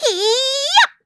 Taily-Vox_Attack4.wav